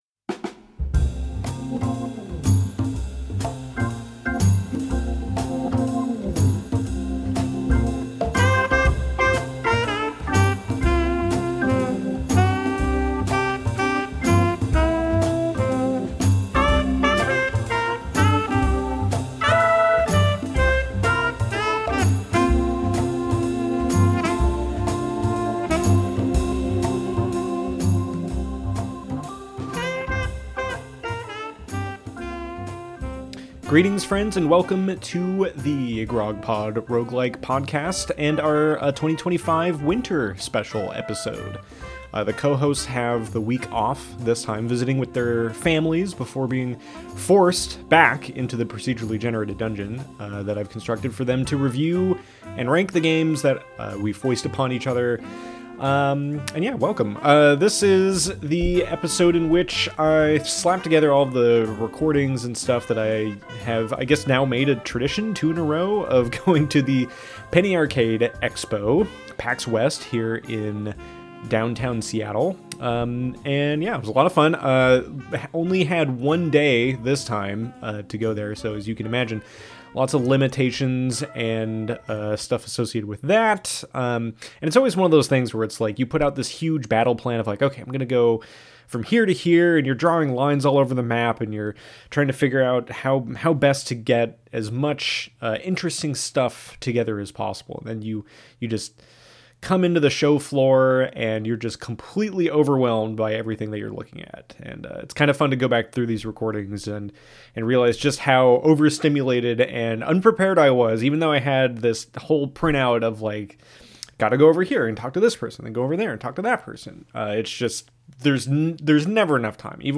With only one day’s worth of ticket time this year, we had to cram as much into one recording as possible before familial duties took precedence. Check the timestamps below for interviews with friends of all sorts and their games and studios!
Live on the streets!